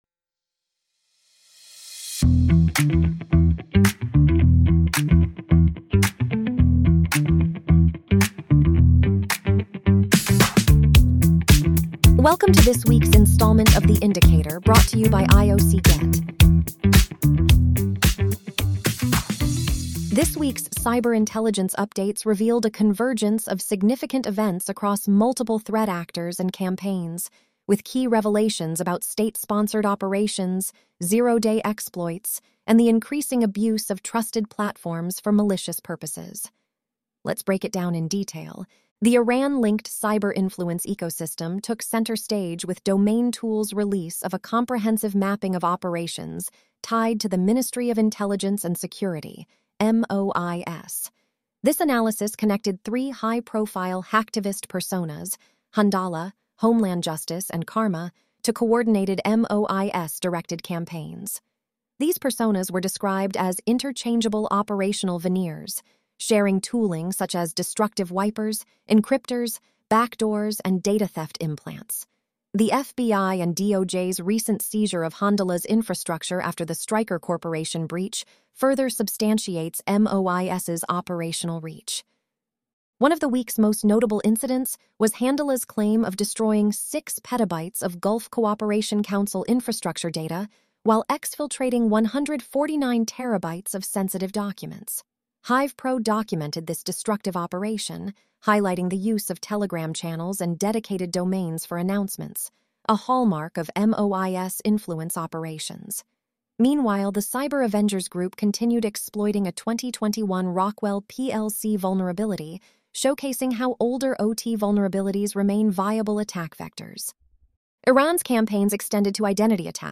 Listen — Weekly Audio Brief